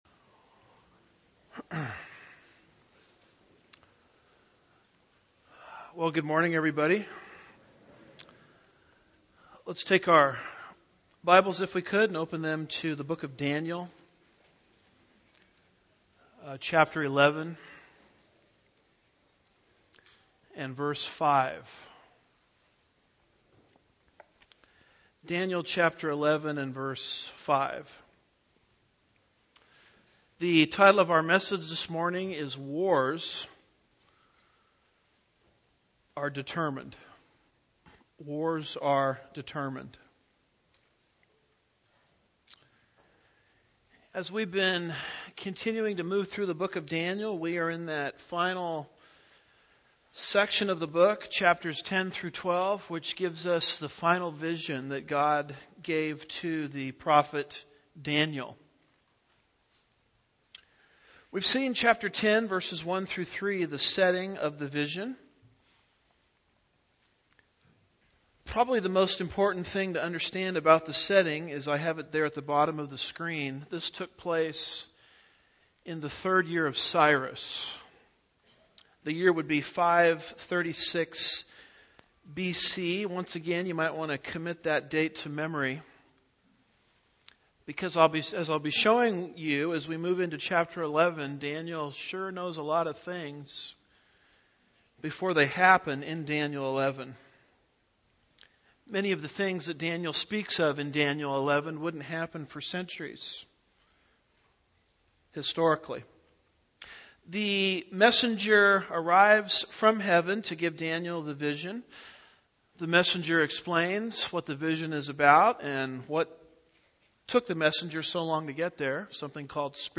12-10-17 Daniel 11:5-12 Lesson 45